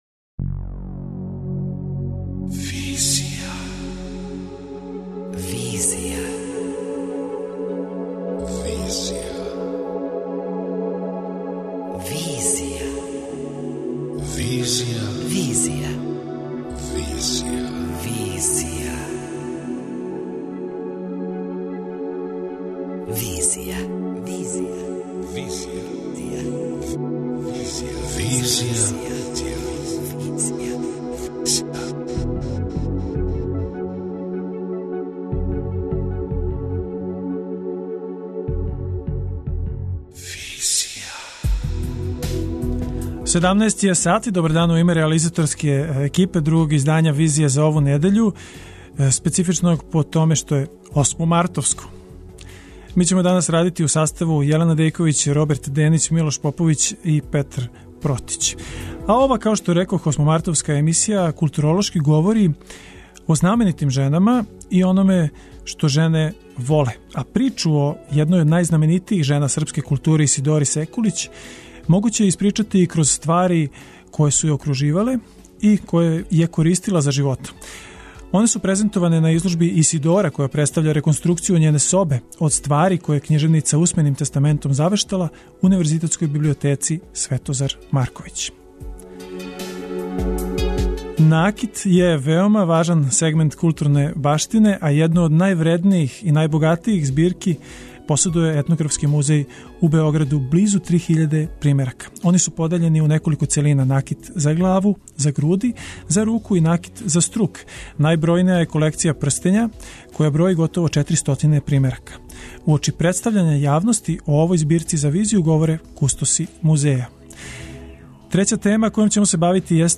Уочи представљања јавности о овој збирци за "Визију" говоре кустоси музеја.